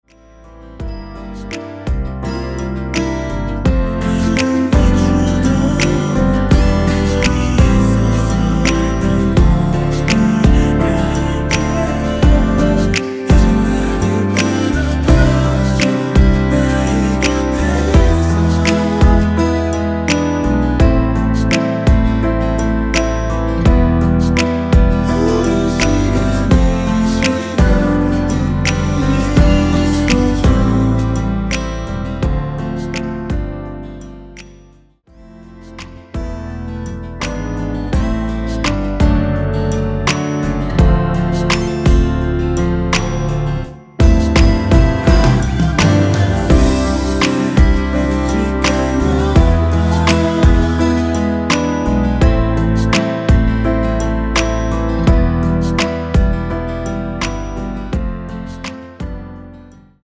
원키에서(-2)내린 코러스 포함된 MR입니다.
앞부분30초, 뒷부분30초씩 편집해서 올려 드리고 있습니다.
중간에 음이 끈어지고 다시 나오는 이유는